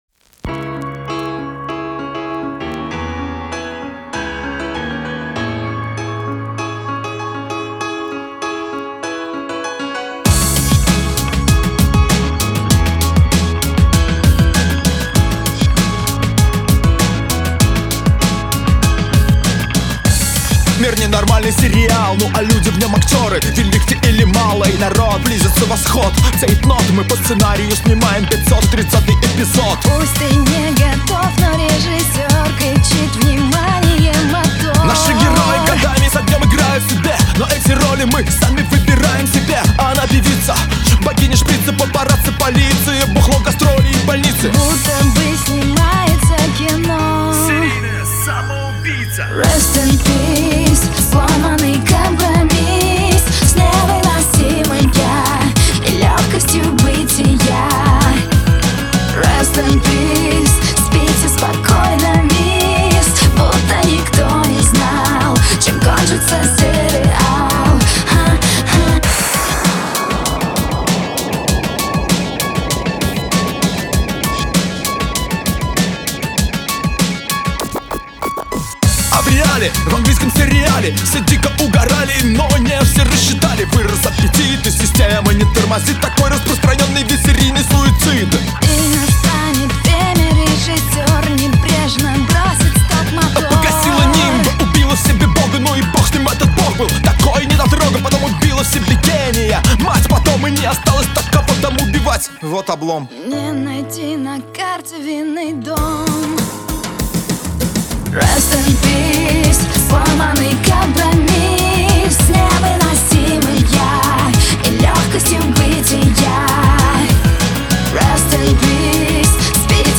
Категорія: Rock